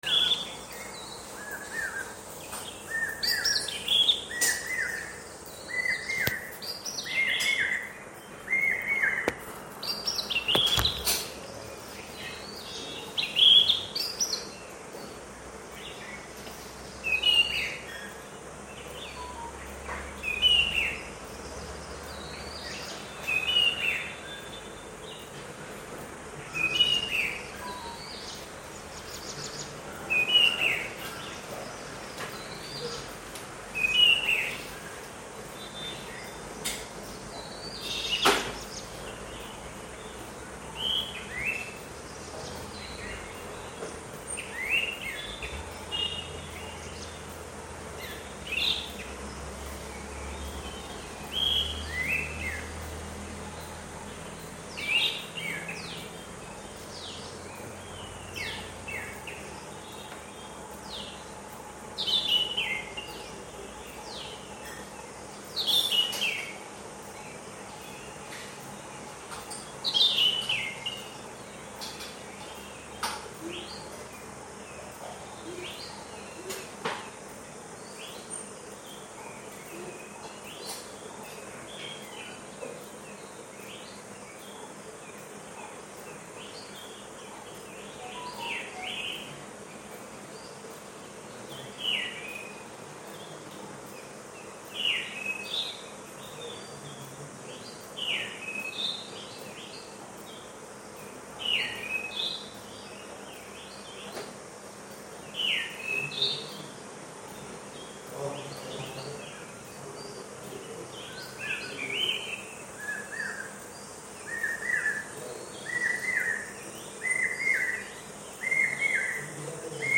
Add to cart Add to wishlist Contact Us Documents cleaned_bird_sound.mp3 Terms and Conditions 30-day money-back guarantee Shipping: 2-3 Business Days Share